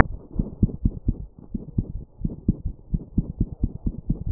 Breed: Domestic Long Hair
Direct HR: 200 beats/min
Heart Rhythm: Underlying rhythm is regular with occasional premature beats auscultated.
Intensity of Heart Sounds: Normal
Extra Sounds – Clicks or Gallops: 2/6 systolic parasternal murmur.
Leo_Auscult_Murmur.wav